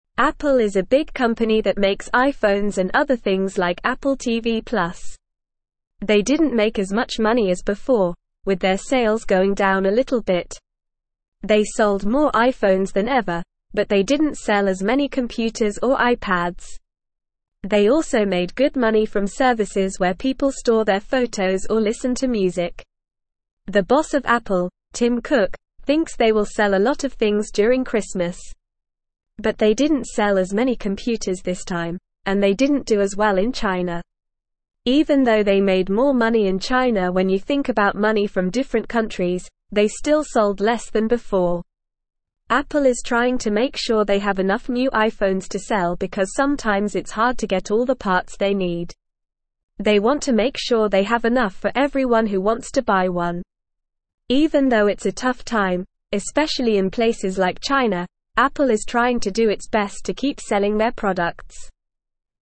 Normal
English-Newsroom-Lower-Intermediate-NORMAL-Reading-Apple-Sells-Many-Things-But-Not-As-Many.mp3